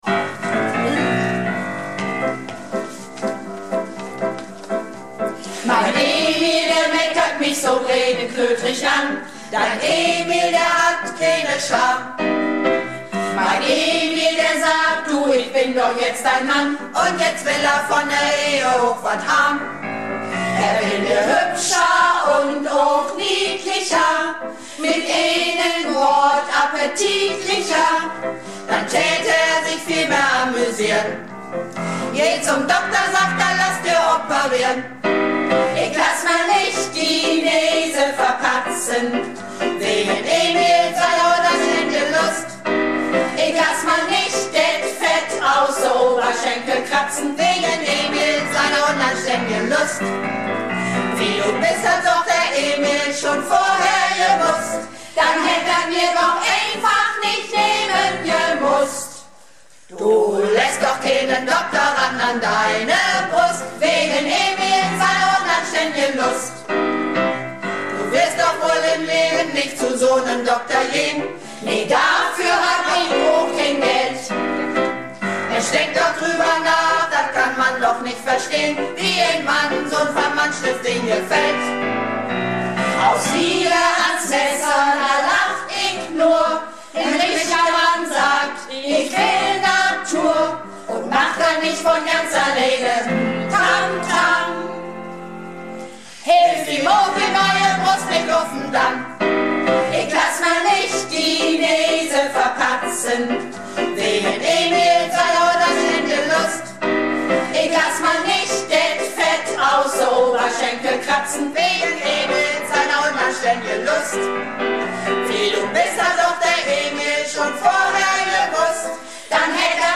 Malle Diven - Probe am 31.05.17